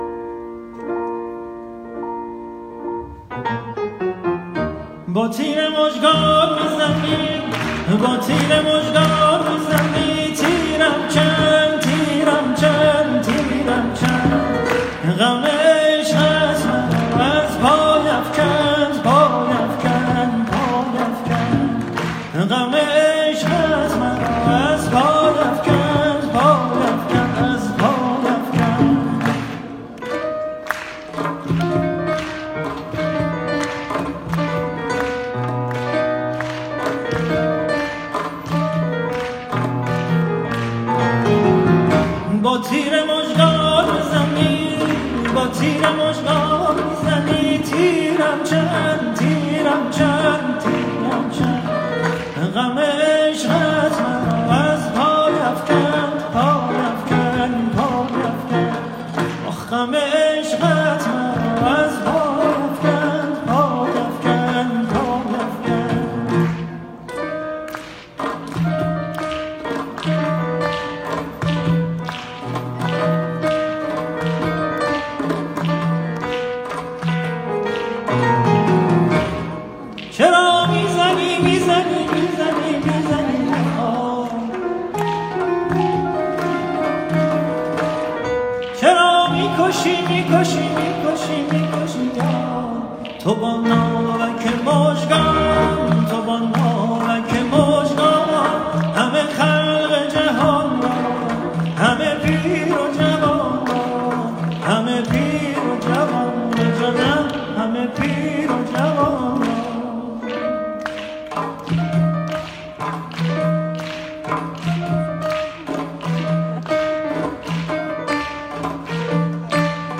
آهنگ سنتی